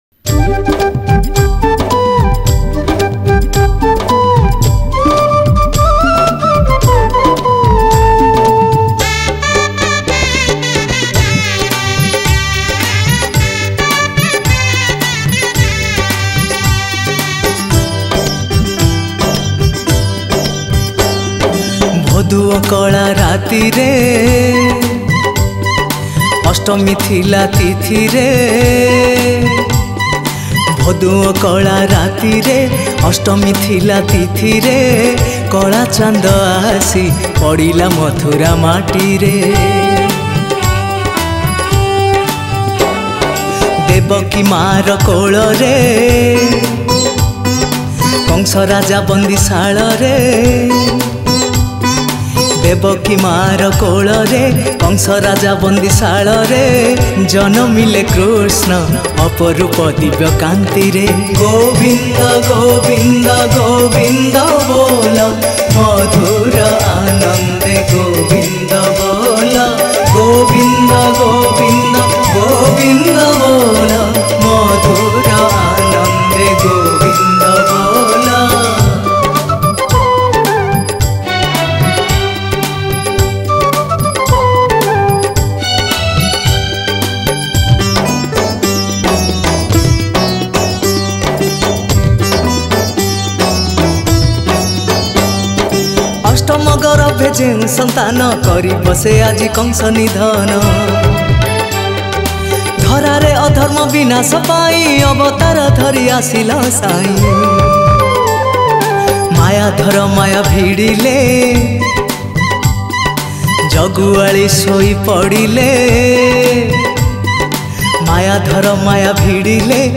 Janmastami Special Odia Bhajan